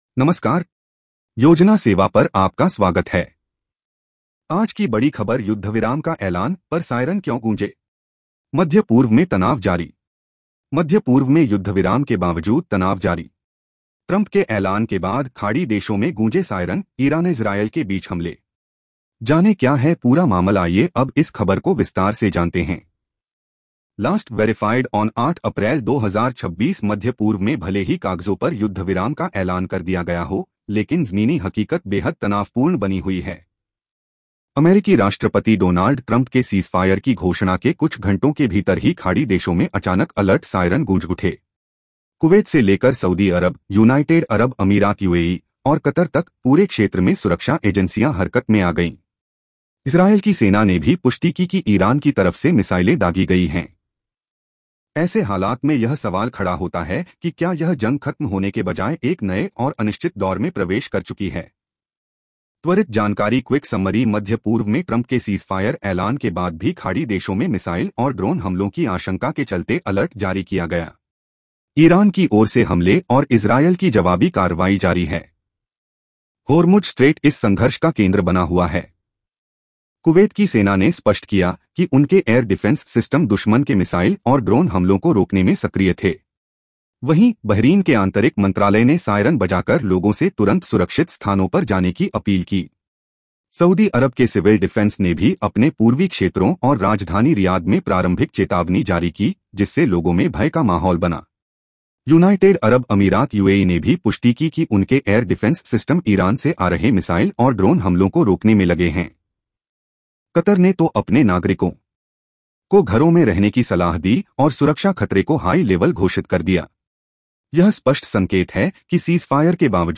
🎧 इस खबर को सुनें (AI Audio):